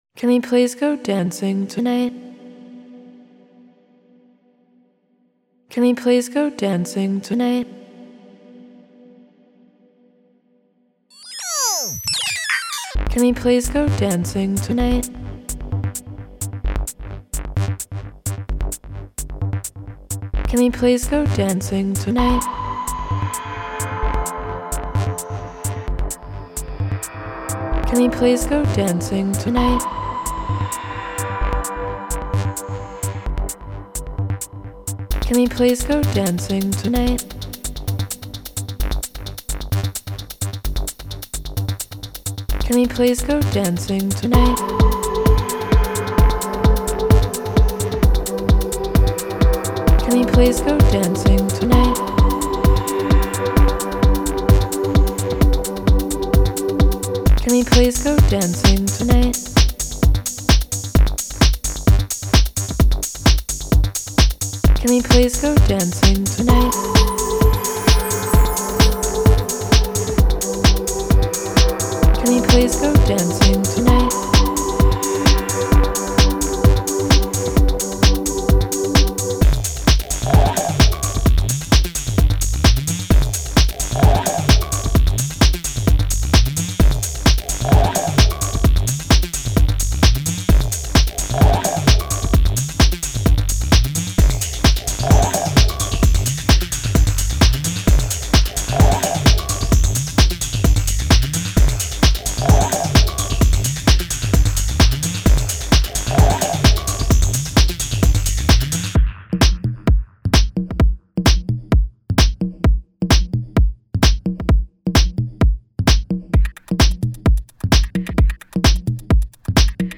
Genre Techno